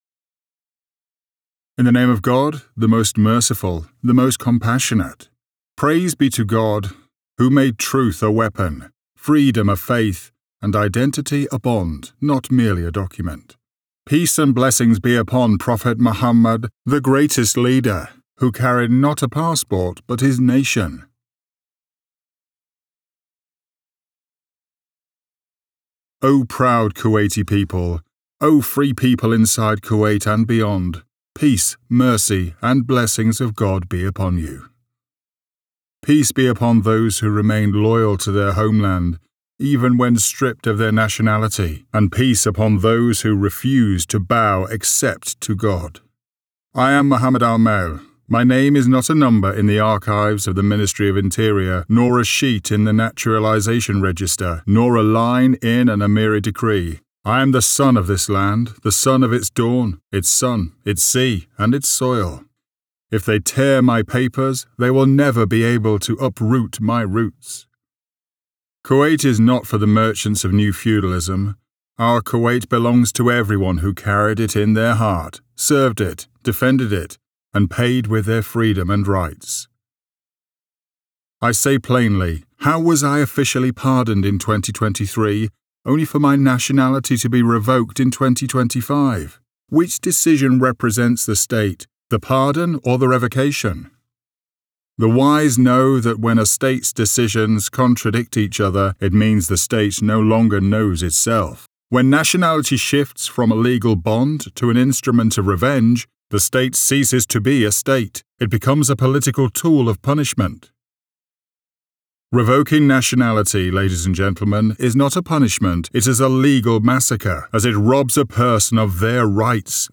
خطاب محظور في الكويت
Dubbing Artist
Location: London, United Kingdom